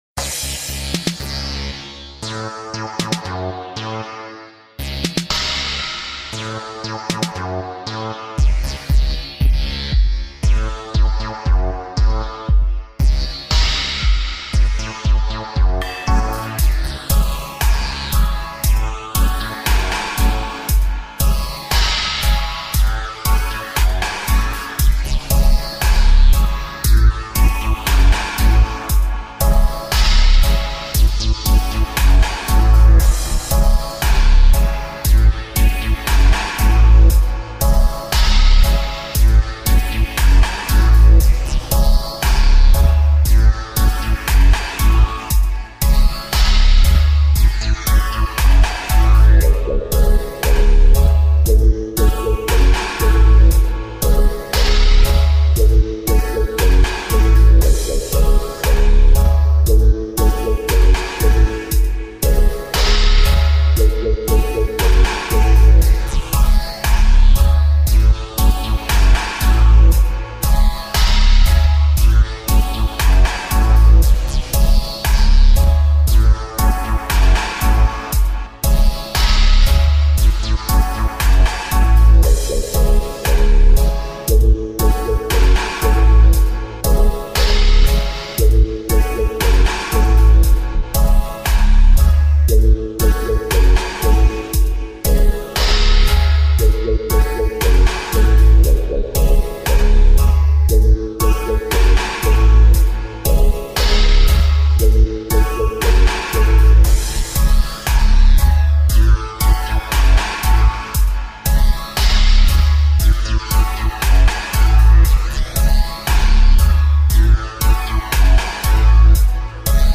RUFF DUBPLATE STYLE